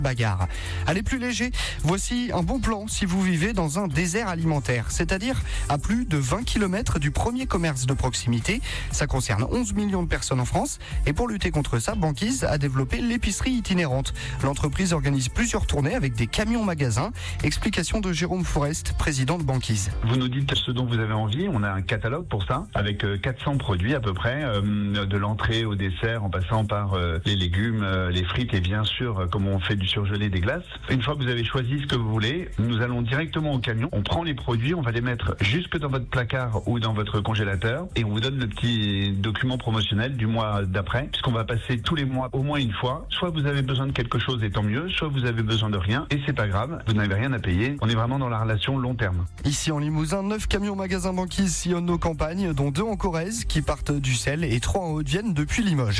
Notre entreprise a fait l’objet d’un sujet diffusé dans trois flashs info à 7h30, 8h00 et 8h30, mettant en lumière notre modèle d’épicerie rurale itinérante et notre rôle dans la lutte contre les déserts alimentaires.